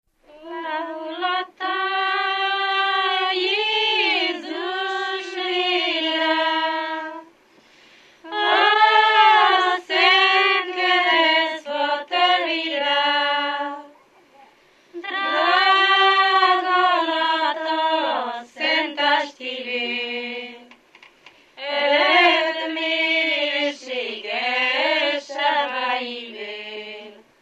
Moldva és Bukovina - Moldva - Lészped
Stílus: 4. Sirató stílusú dallamok